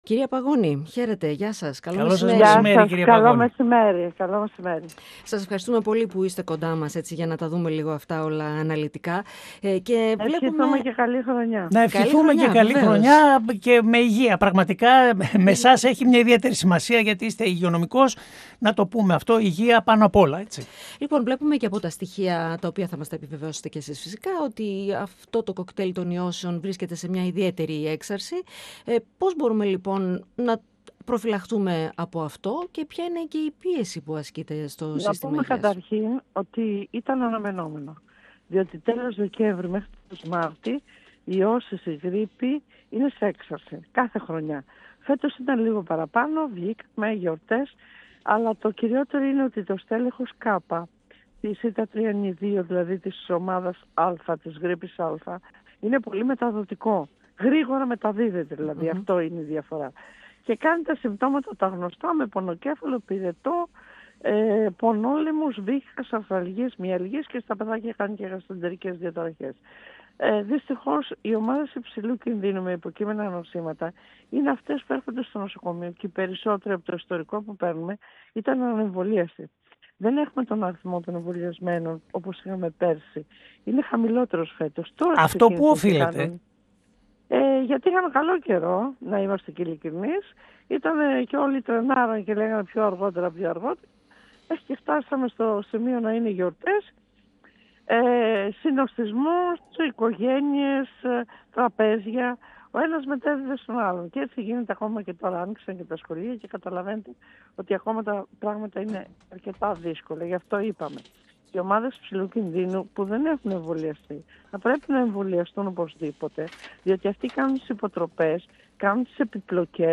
μιλώντας στον 102fm και την εκπομπή “Επόμενη Στάση”